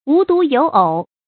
無獨有偶 注音： ㄨˊ ㄉㄨˊ ㄧㄡˇ ㄡˇ 讀音讀法： 意思解釋： 獨：一個。偶：一對。不只一個；竟然還有配對的。